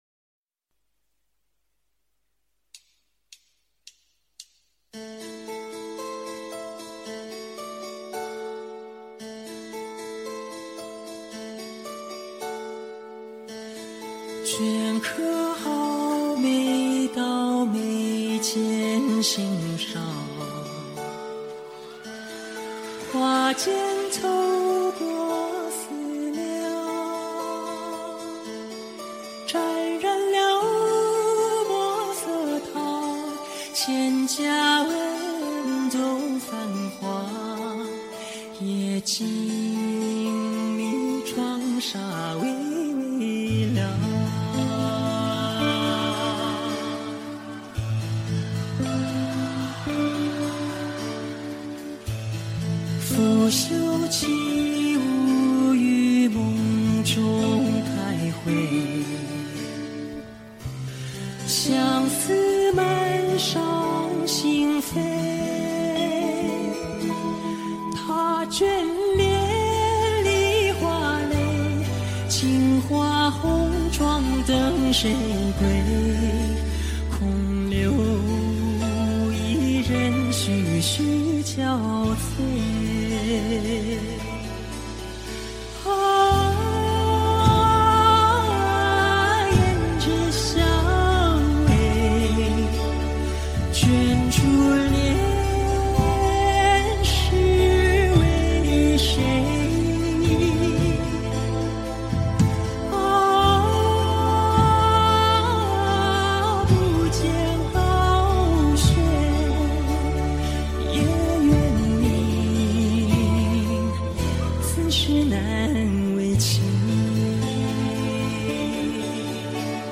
背景音乐
弦音幽怨，曲调萦回，声声唱尽忧伤。
缠绵但凭悱恻，缓行腔、余味悠扬。